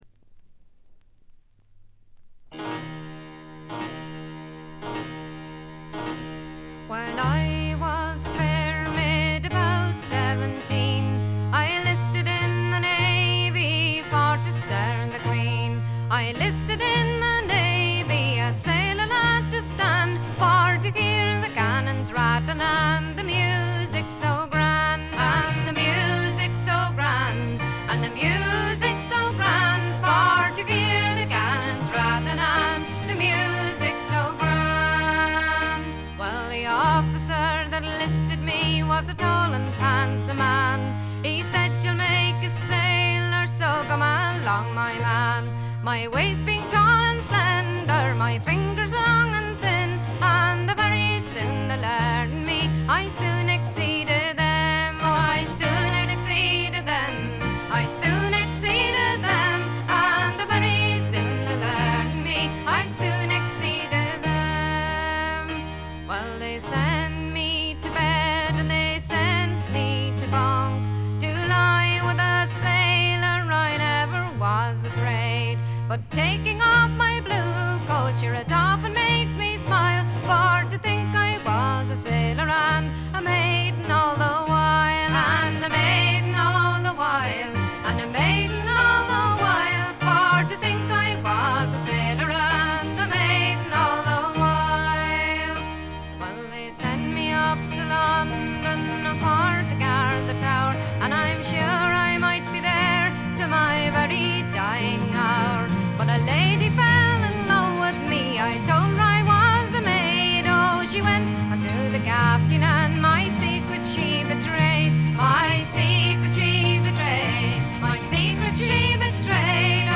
ほとんどノイズ感無し。
時に可憐で可愛らしく、時に毅然とした厳しさを見せる表情豊かで味わい深いヴォーカルがまず最高です。
これにパイプやフルートなどがからんでくると、そこはもう寒そうな哀愁漂う別天地。
試聴曲は現品からの取り込み音源です。
vocals, harpsichord, bodhran